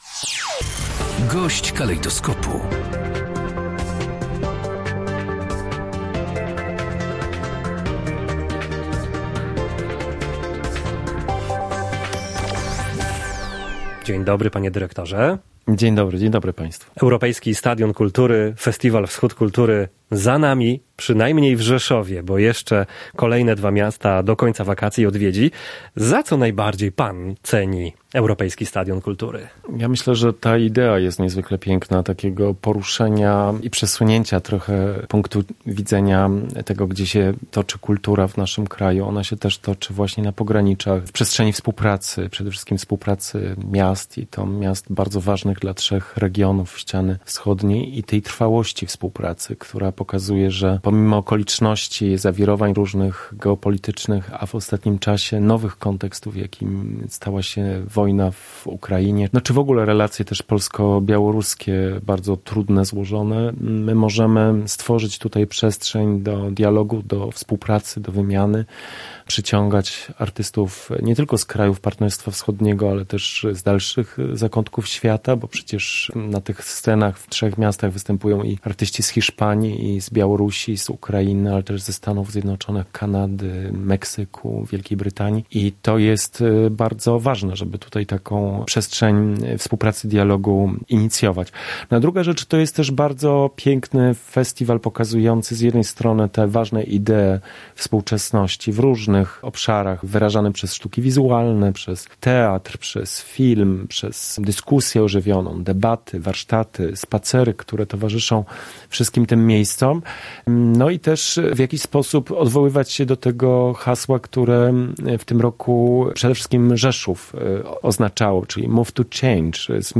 -Rzeszów jest przykładem wielokulturowego miasta, które przygotowało ofertę dla różnorodnych środowisk – podkreślał na naszej antenie Robert Piaskowski, dyrektor Narodowego Centrum Kultury.